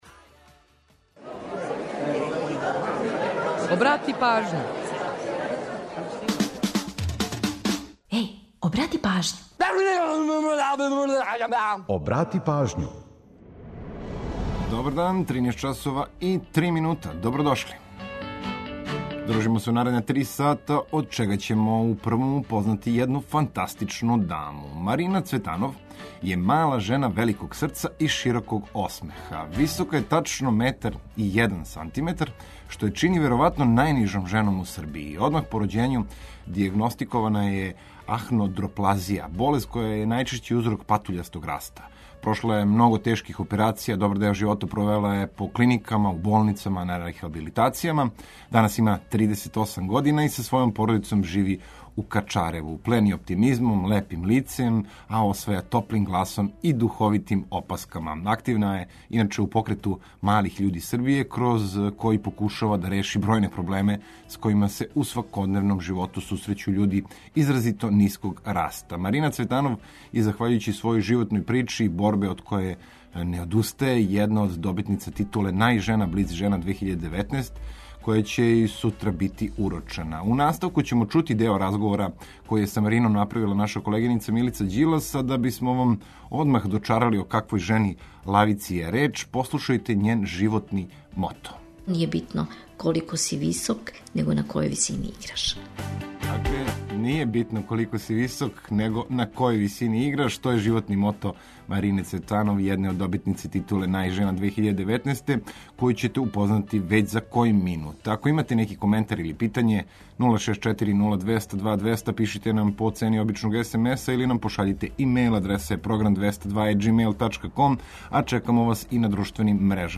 Плени оптимизмом и лепим лицем, а осваја топлим гласом и духовитим опаскама.
Сервисне информације и наш репортер са подацима о саобраћају помоћи ће многима у организовању дана, а „Културни водич” је ту да бисмо вам предложили које манифестације широм Србије можете да посетите. Подсећамо на приче иза песама, прослављамо и рођендане албума, синглова и музичара, а пола сата резервишемо само за нумере из Србије и региона.